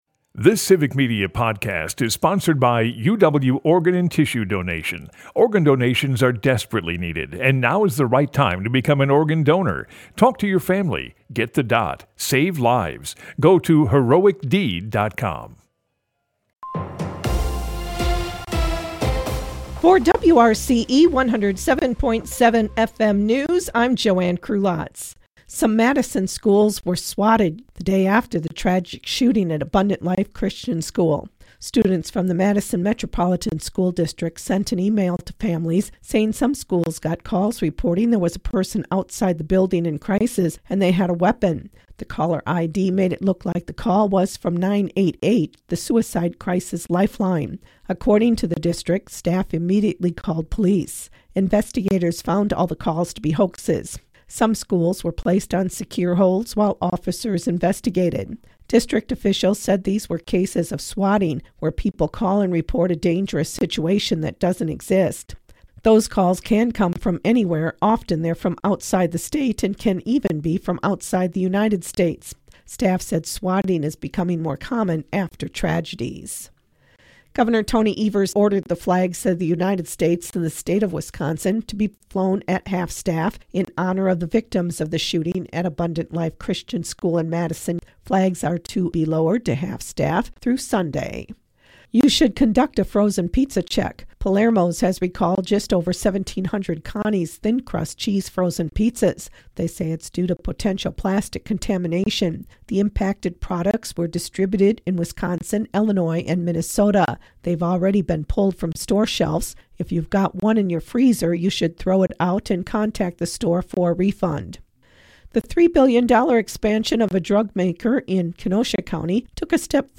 WRCO and WRCE's News from the Center has your state and local news, weather, and sports for Richland Center, delivered as a podcast every weekday.